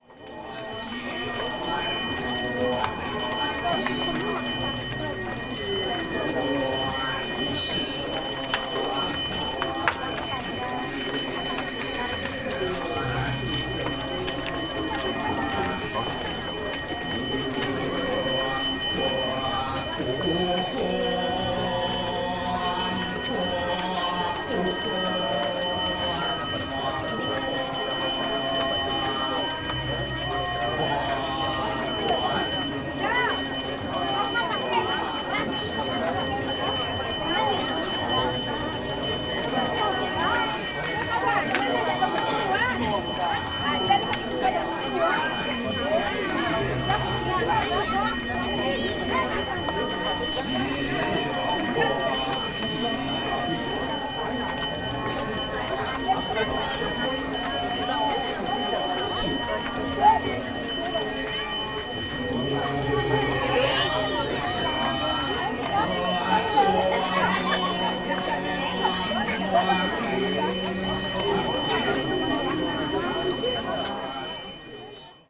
click to LISTEN the live meditation soundscape :::... recording from the same spot. With those sounds, volcanic hill surroundings, incence smoke and the whole vibe in the atmosphere they go into trance to communicate with their Gods during prayers.
Pura_Besakih_meditatsioon.mp3